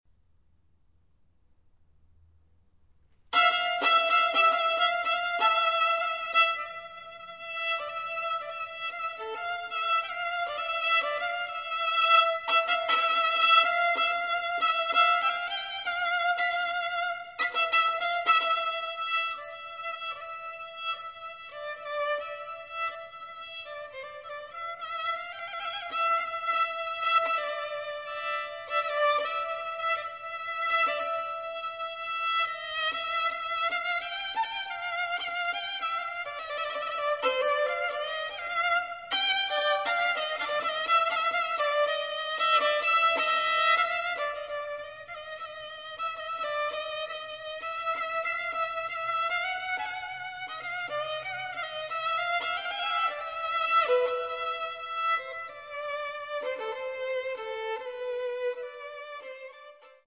Violin
Rosslyn Hill Chapel, London